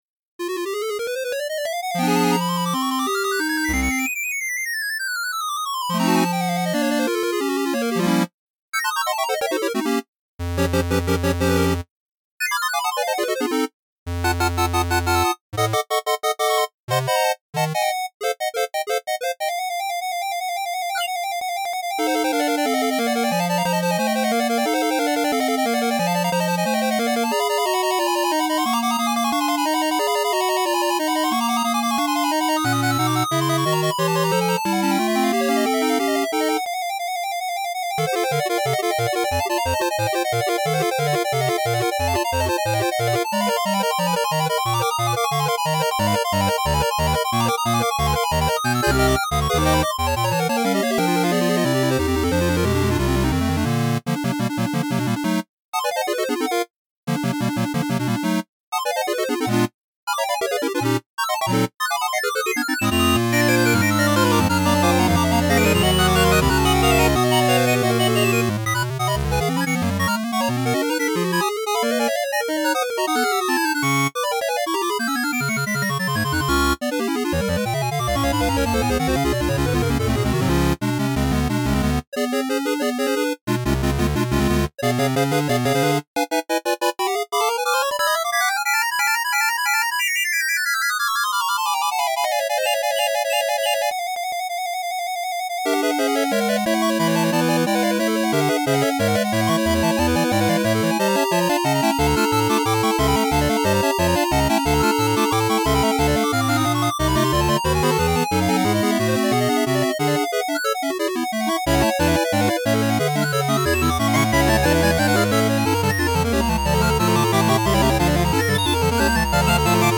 Feux Follets 8 Bit Remix!